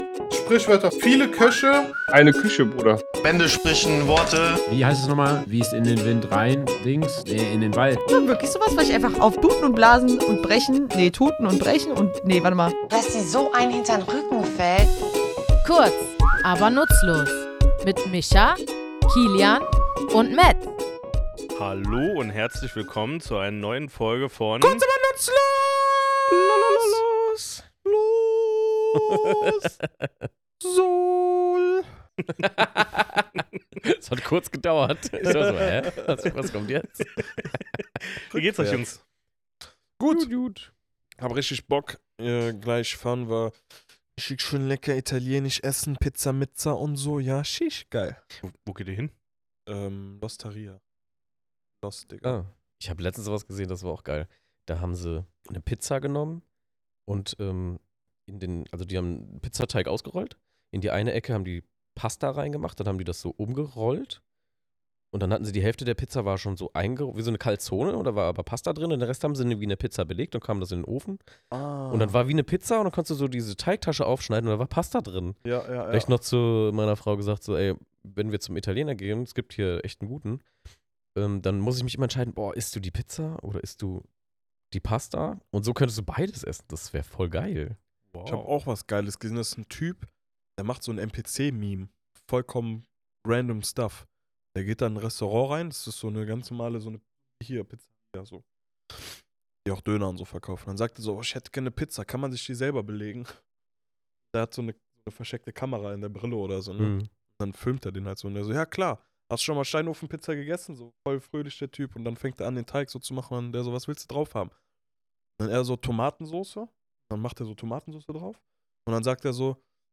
Warum behaupten wir ausgerechnet mit diesem Satz unsere Unschuld – und wer ist eigentlich dieser Hase? Wir, drei tätowierende Sprachliebhaber, graben in unserem Tattoostudio tief in der Geschichte dieser Redensart und klären, was ein Heidelberger Jurastudent damit zu tun hat.